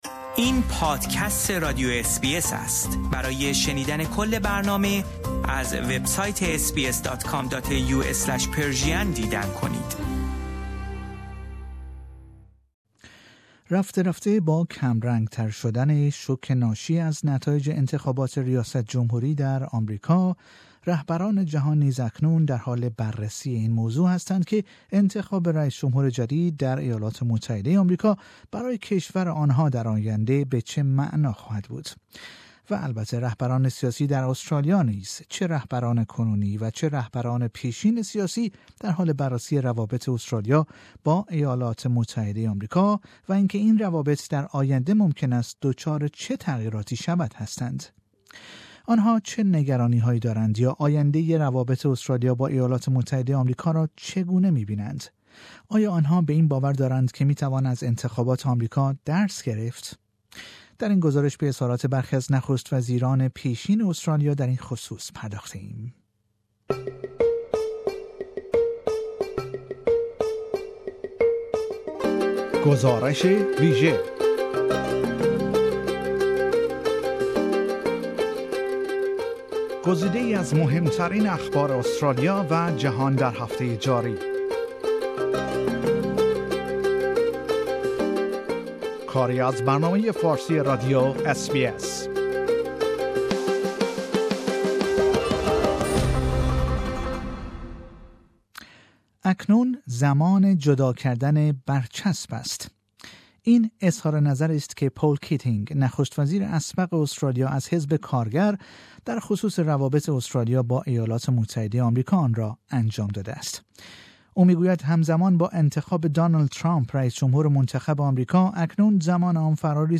.در این گزارش به اظهارات برخی از نخست وزیران پیشین استرالیا در این خصوص پرداخته ایم